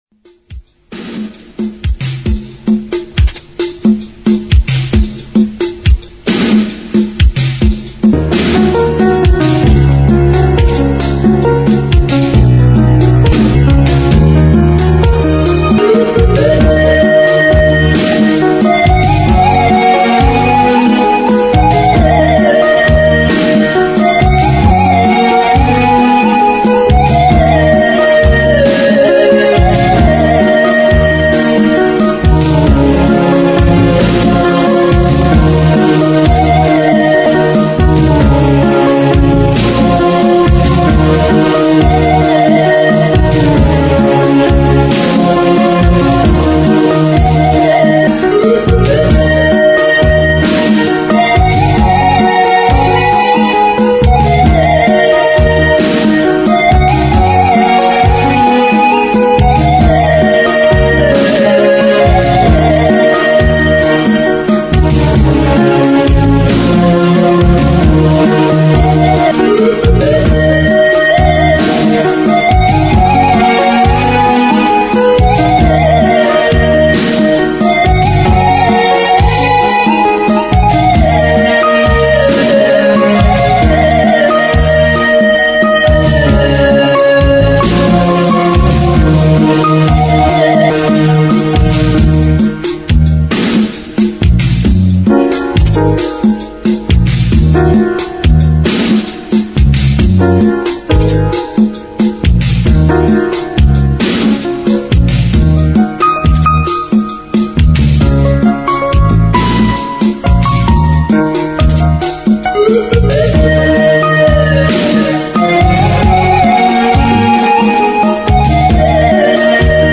musique instrumentale